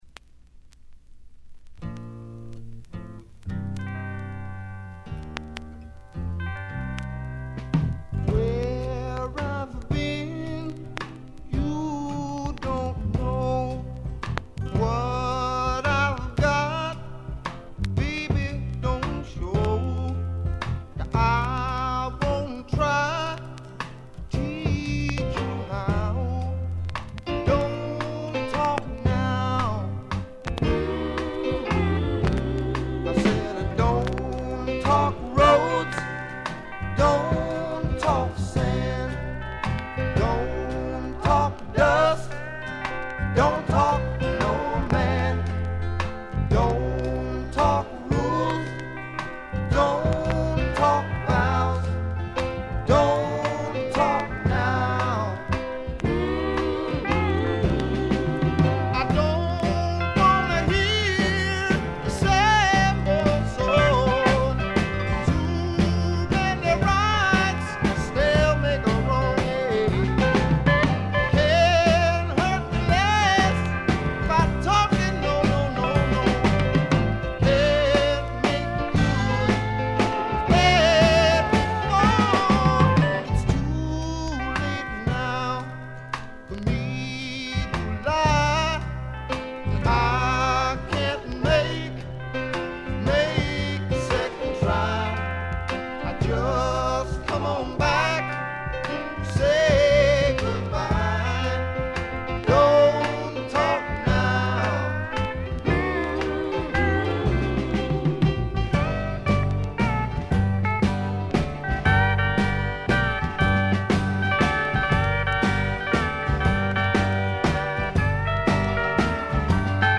A1からA2の曲間とA2序盤、B2の静音部でチリプチが目立ちますが鑑賞を妨げるほどではないと思います。
で、内容はザ・バンドからの影響が色濃いスワンプ裏名盤であります。
試聴曲は現品からの取り込み音源です。